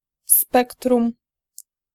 Ääntäminen
Ääntäminen Tuntematon aksentti: IPA: /ˈspɛktrũm/ Haettu sana löytyi näillä lähdekielillä: puola Käännös Substantiivit 1. spectrum Suku: n .